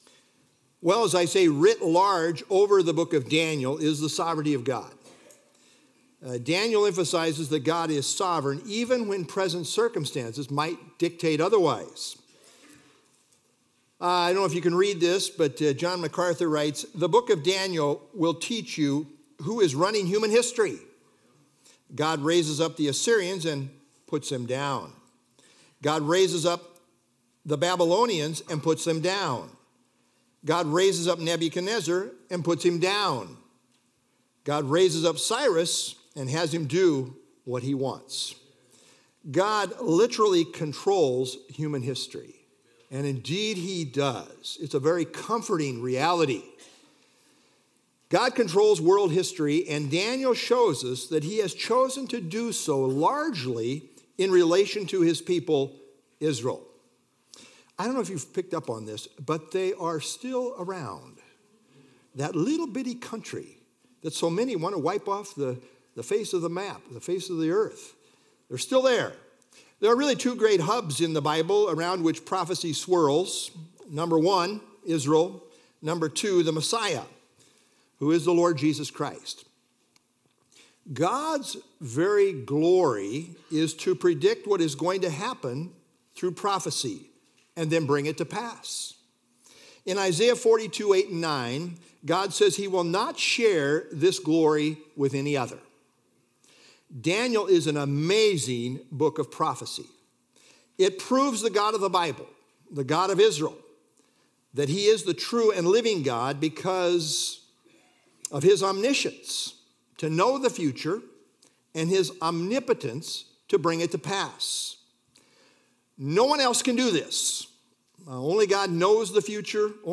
Download FilesDaniel 1 - Sermon - Feb 9 2025Daniel 1